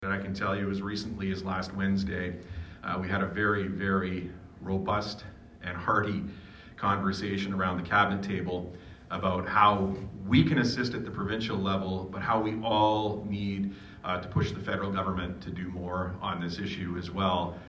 Minister of Energy and Bay of Quinte riding MPP Todd Smith speaks at a breakfast meeting of the Belleville Chamber of Commerce Wednesday October 11 2023.